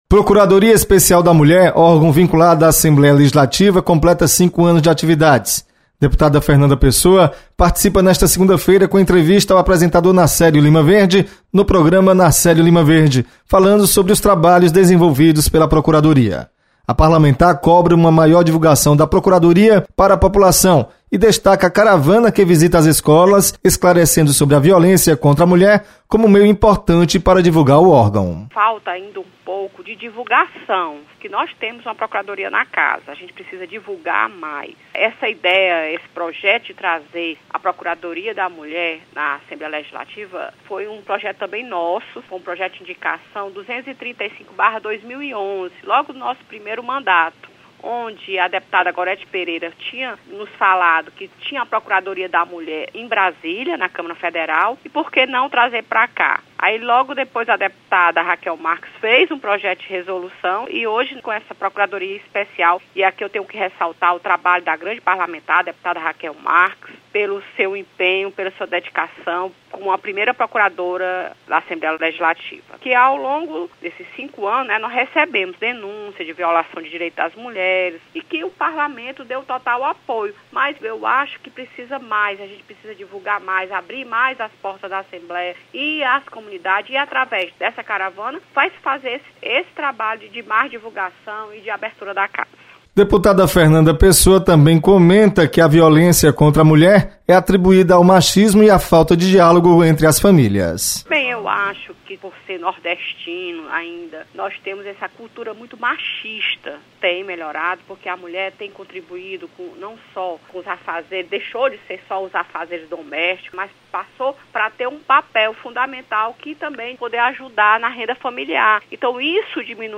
Você está aqui: Início Comunicação Rádio FM Assembleia Notícias Procuradoria